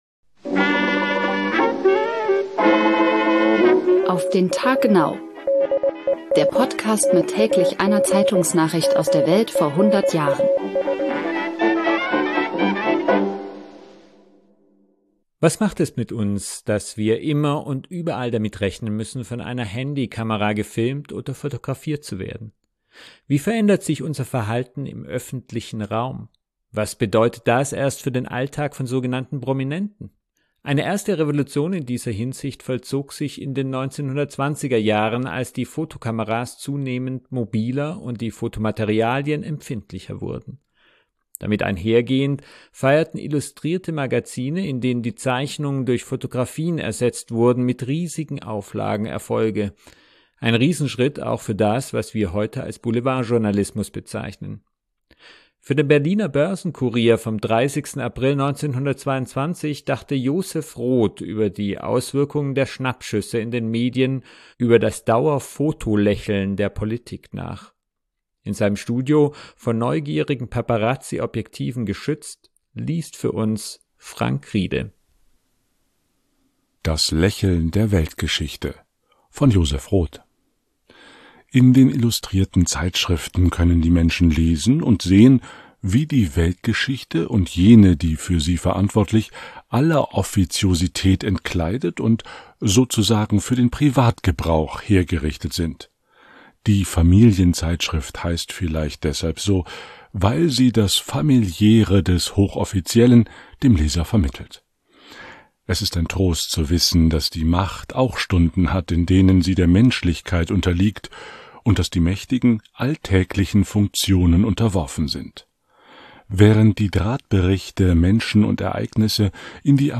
In seinem Studio, vor